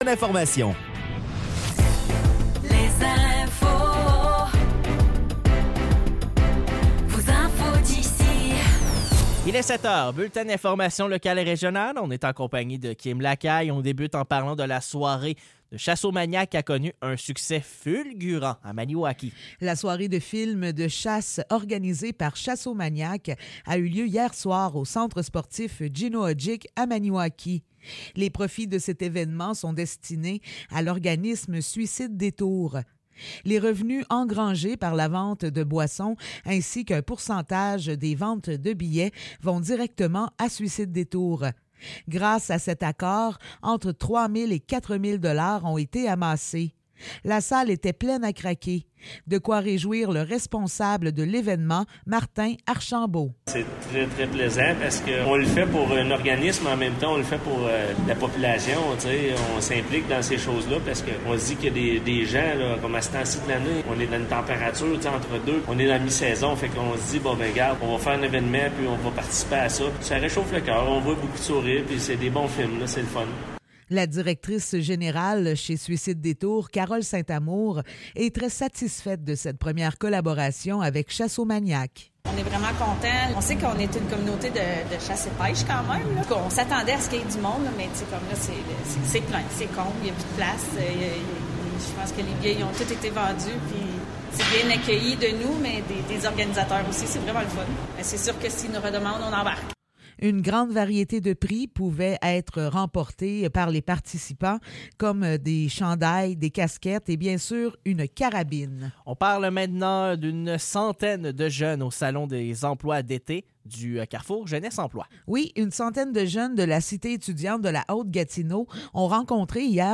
Nouvelles locales - 5 avril 2024 - 7 h